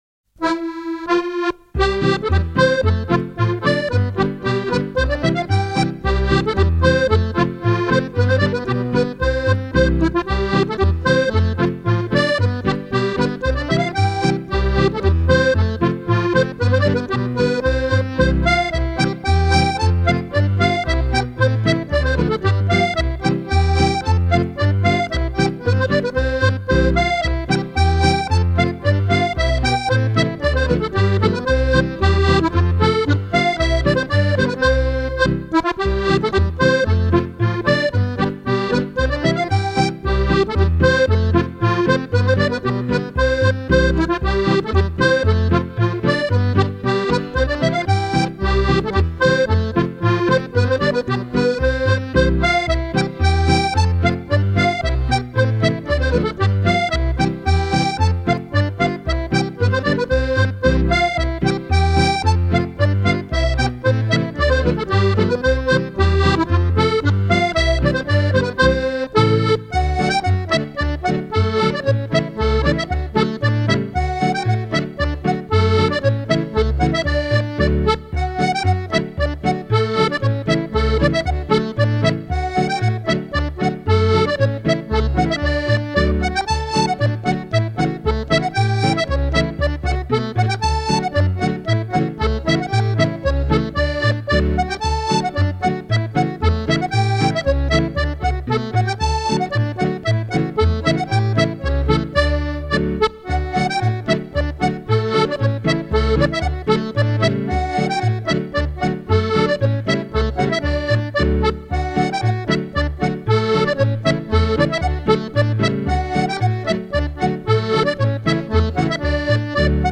5 x 32 bar Scottish Reels